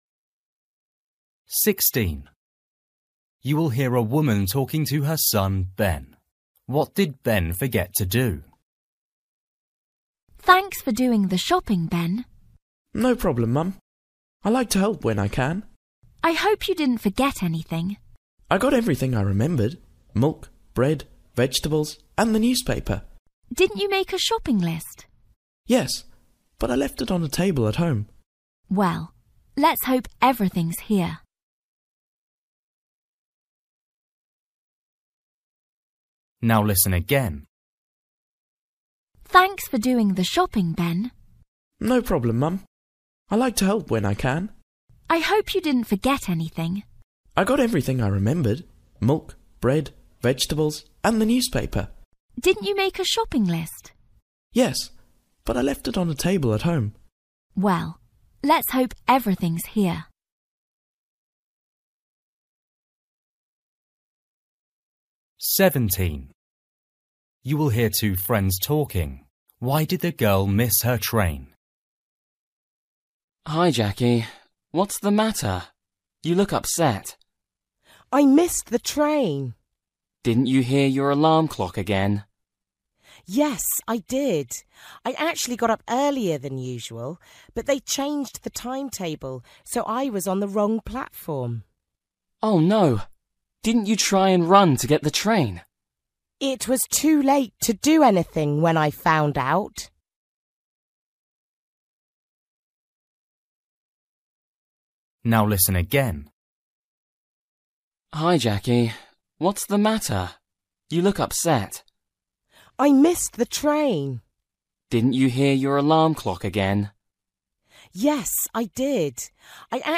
Listening: everyday short conversations
17   You will hear two friends talking. Why did the girl miss her train?
20   You will hear a husband and wife talking. What are they doing?